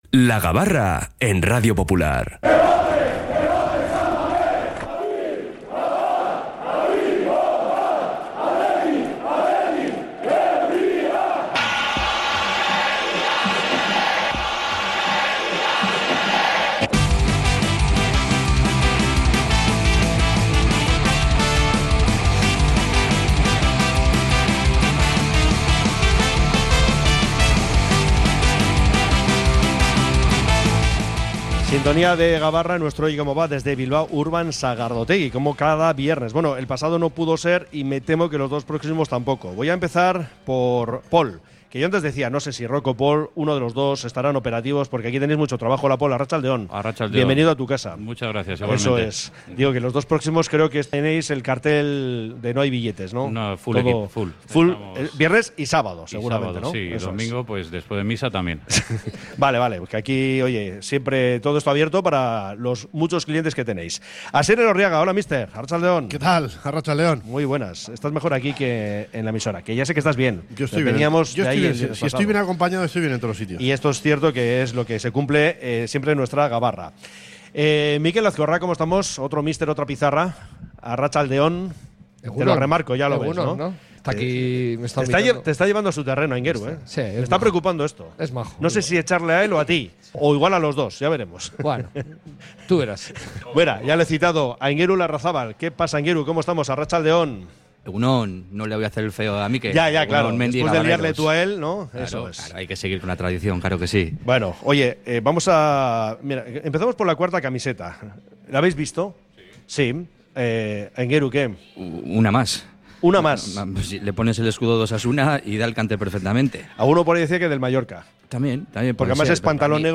Desde Bilbao Urban Sagardotegi hemos analizado, como cada viernes, toda la actualidad rojiblanca